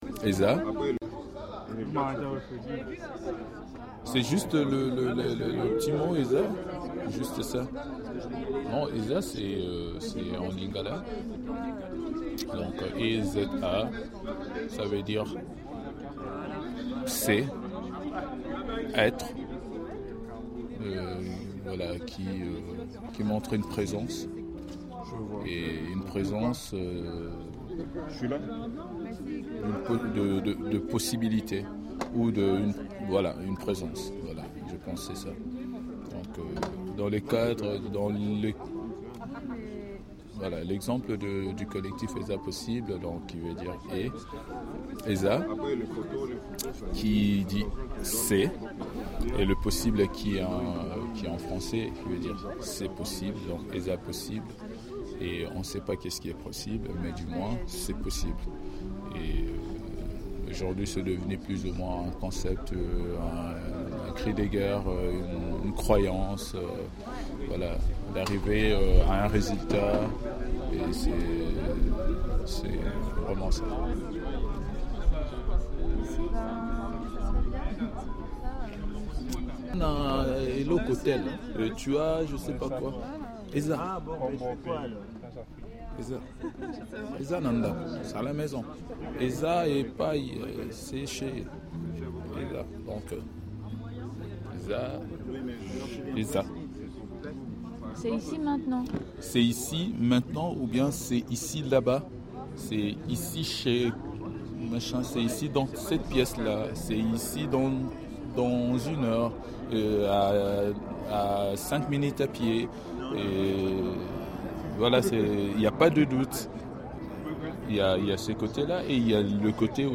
Vernissage de l’exposition des collectifs Eza Possibles et OKUP